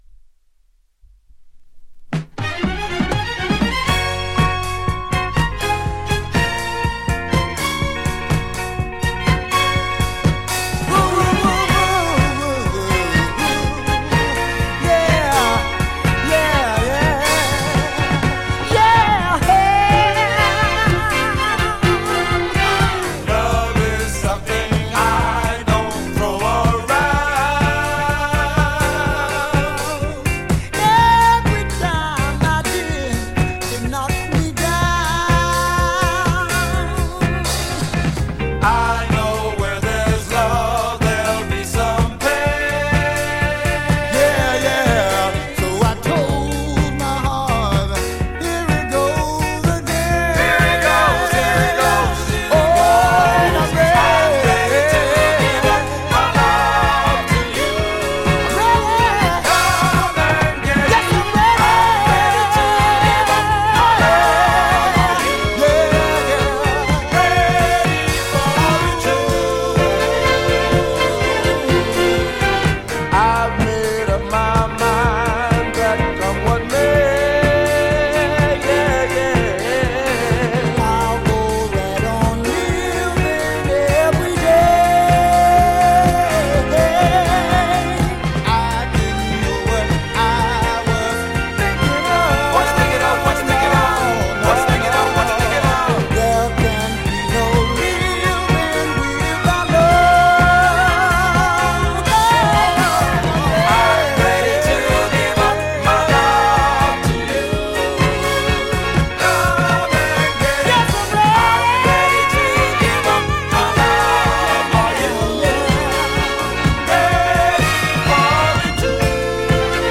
ジャンル(スタイル) DISCO / SOUL